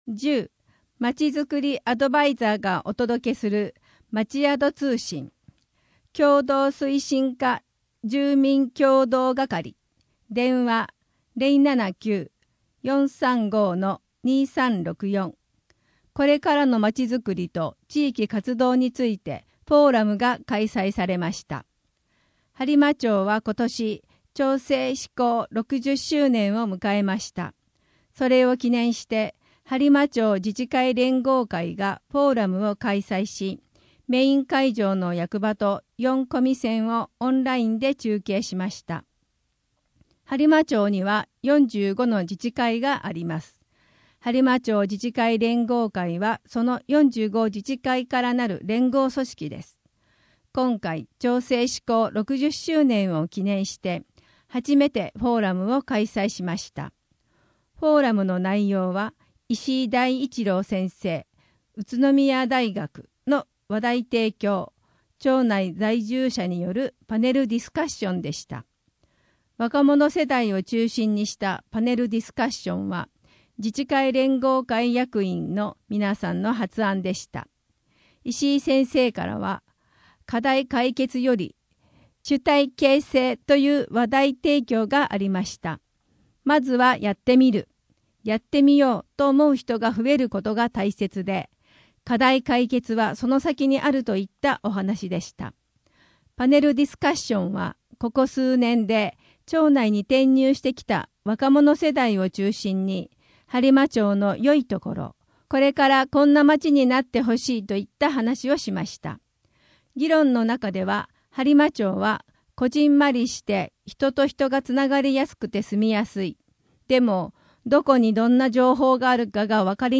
声の「広報はりま」2月号
声の「広報はりま」はボランティアグループ「のぎく」のご協力により作成されています。